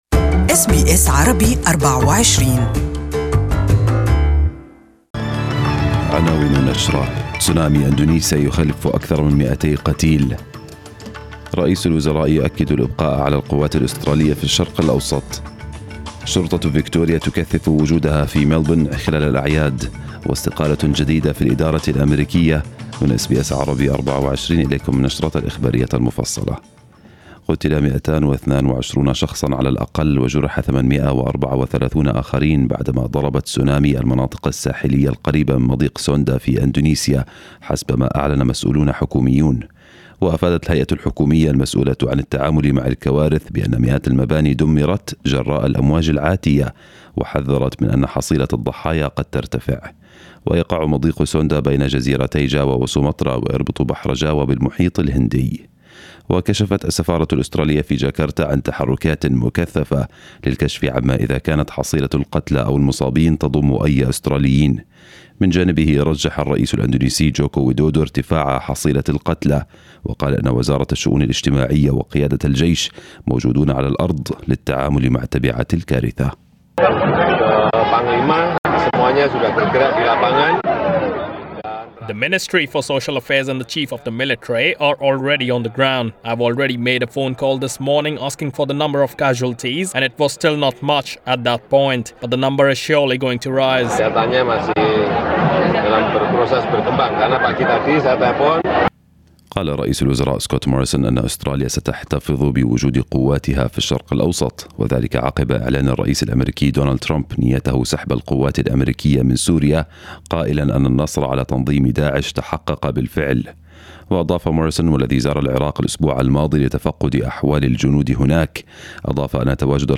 Morning news bulletin in Arabic.